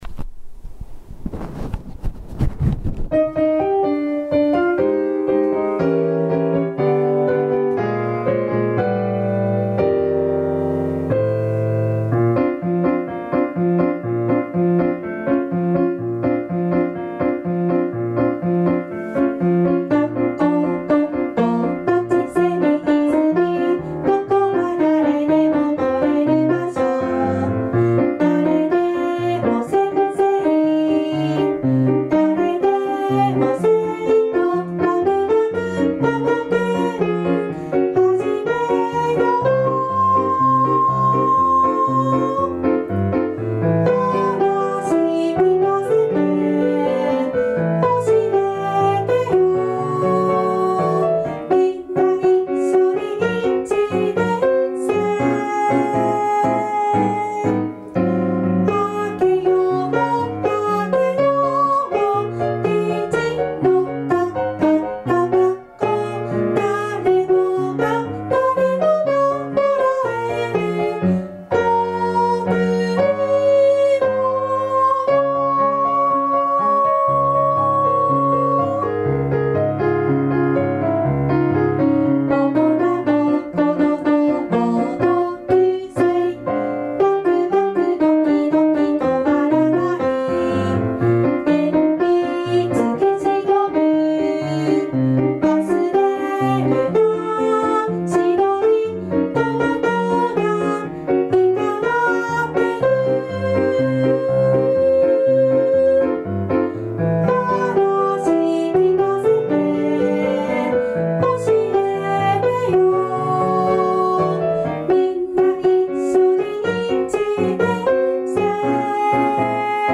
まちセミ・IZUMI校歌（歌入り）
machisemiizumi-utaari.mp3